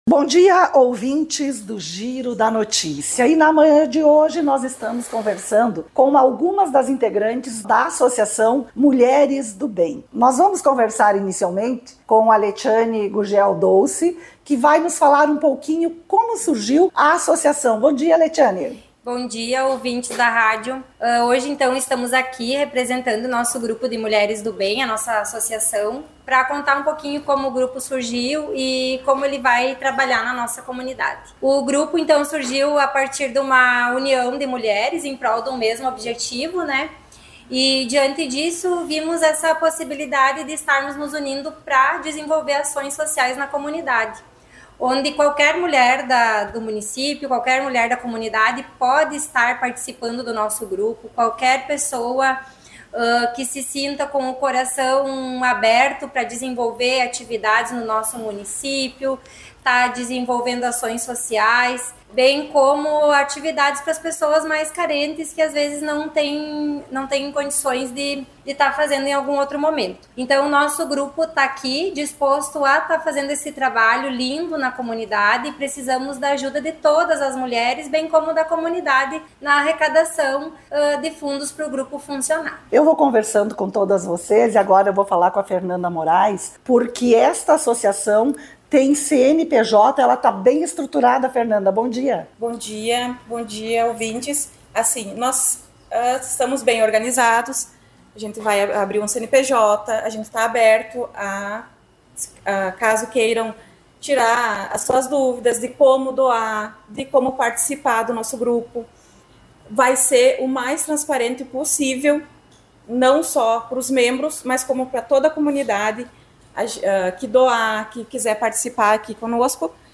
Confira a seguir a entrevista que realizamos com as integrantes do grupo Associação Mulheres do Bem para a nossa programação do Giro da Notícia.